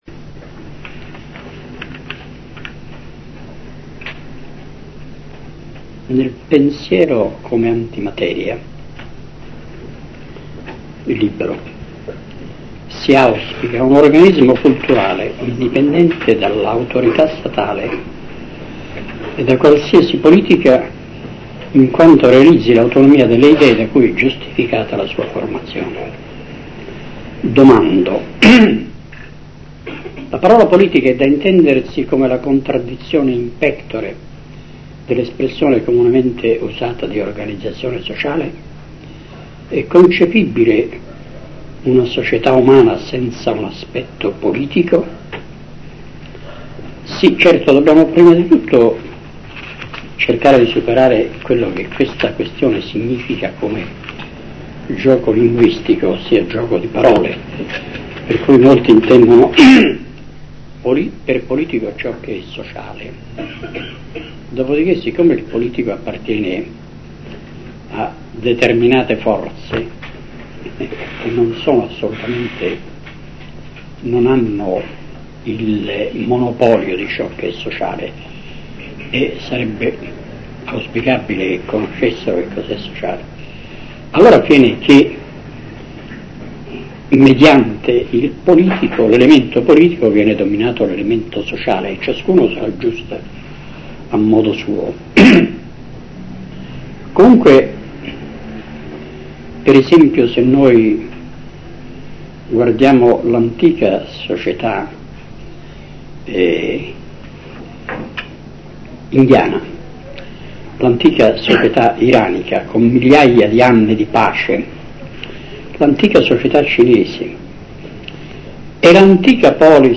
Conferenza Del 20 Dicembre 1978